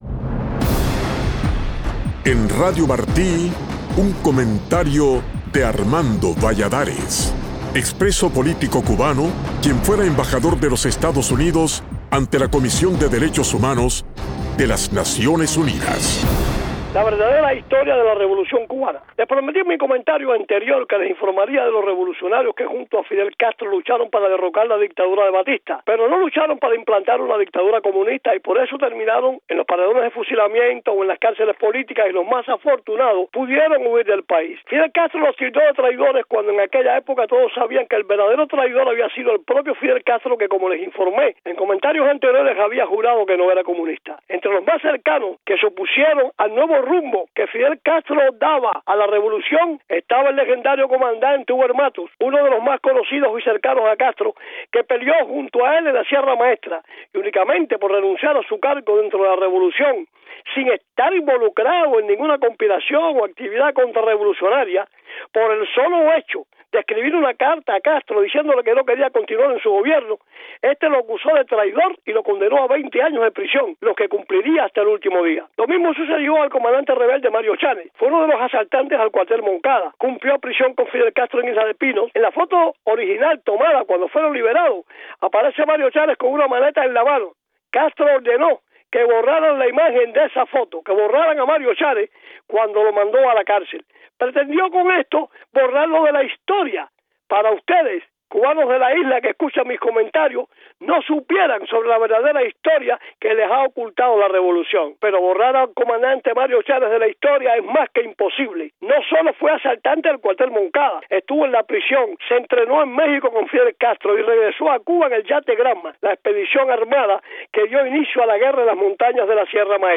Muchos de los hombres y mujeres que lucharon junto a Fidel Castro para derrocar a la tiranía de Batista terminaron en los paredones de fusilamiento o en las cárceles del nuevo régimen por oponerse a que la revolución implantara en Cuba una dictadura comunista. Del tema nos habla en el comentario de hoy el embajador Armando Valladares.